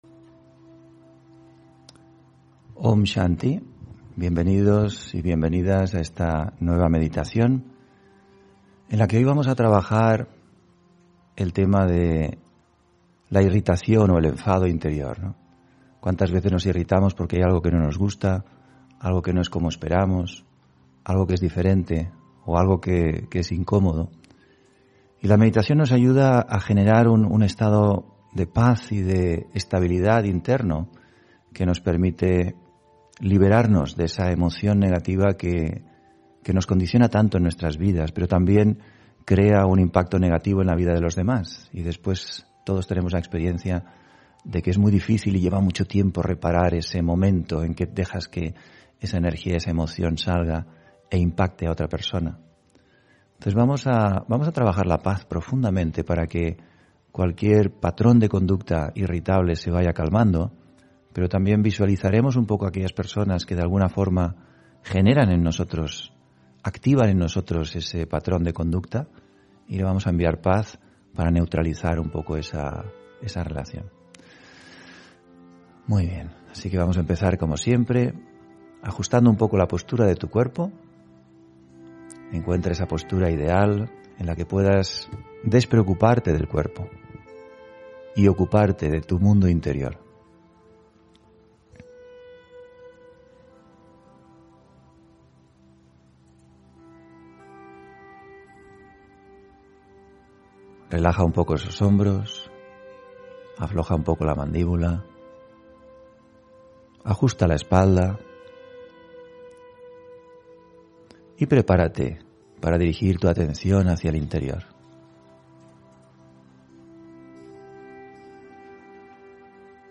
Meditación de la mañana: Conquistar la irritabilidad (6 Septiembre 2021)